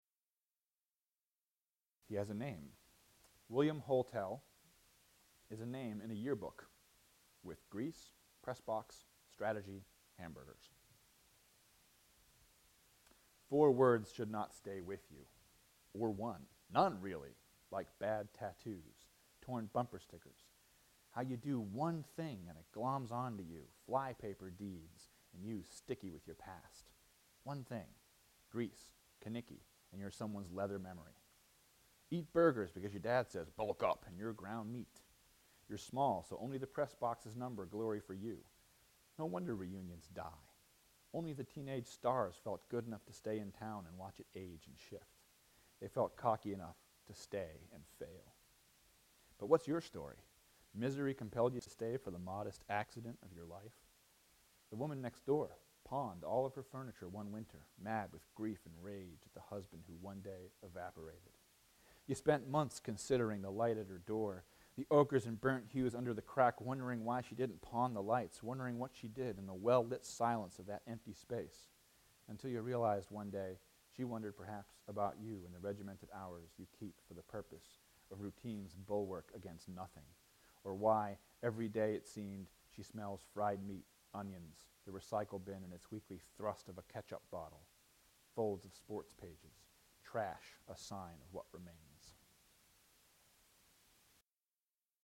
He-Has-a-Name-live.mp3